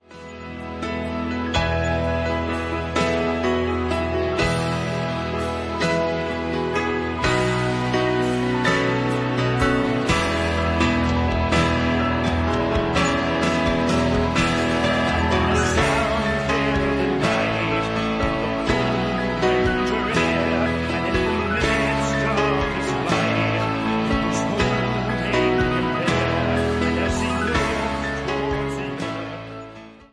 The live piano accompaniment that plays with the album